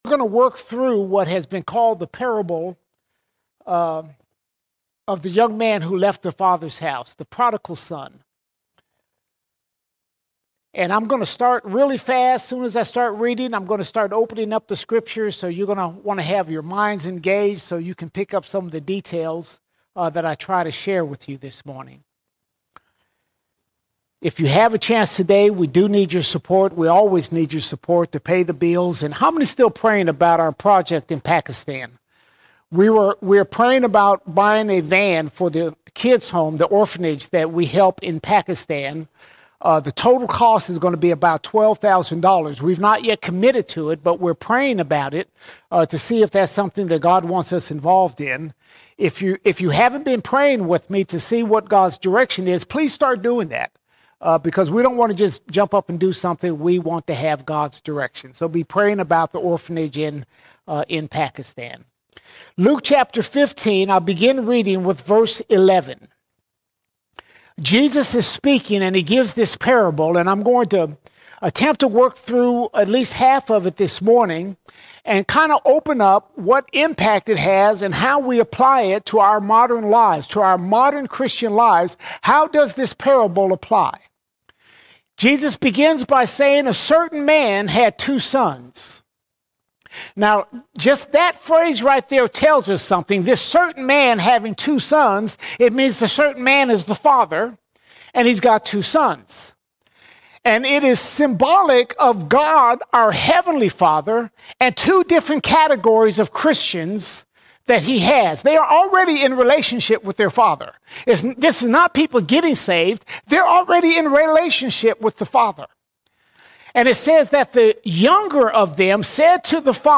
10 March 2025 Series: Sunday Sermons All Sermons Leaving The Father Leaving The Father In the Fathers house we have all we need.